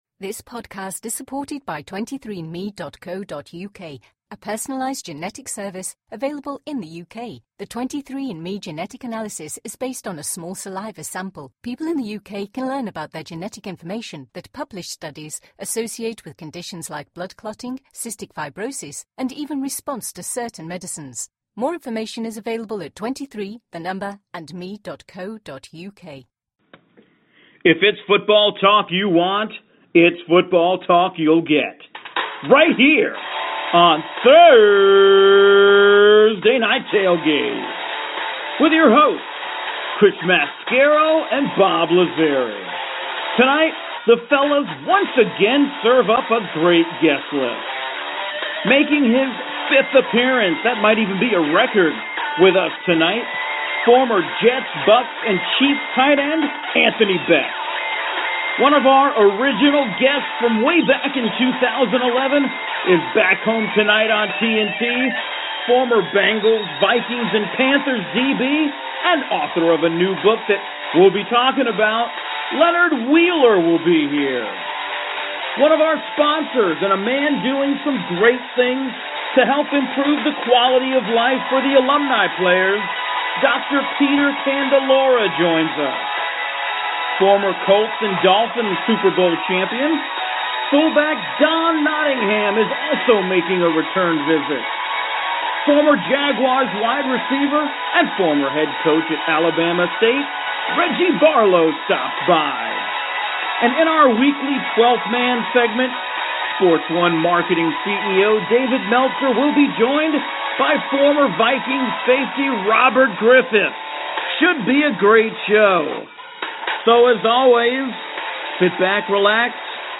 they talk with current and former players and coaches from around the NFL & CFL